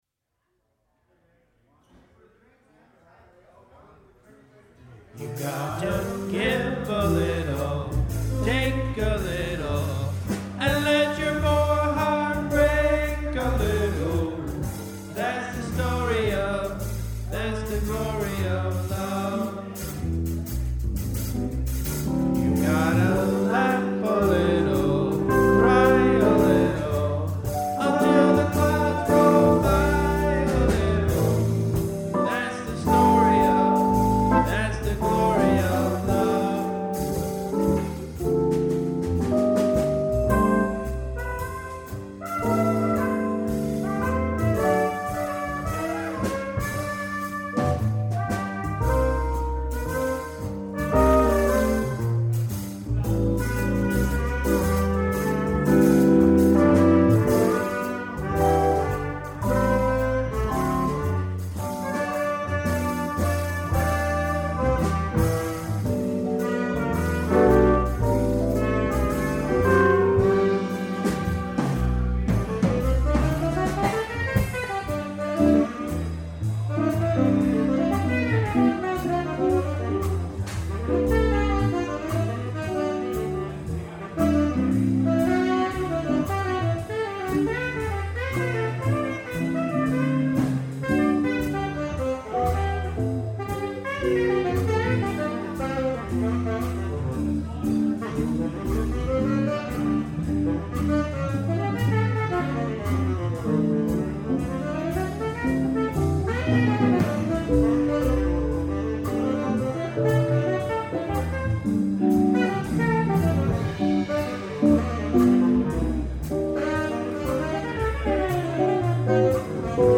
Vocals, Trumpet, Flugelhorn, Rhythm Guitar.
Lead Guitar
Keyboard
Bass
Drums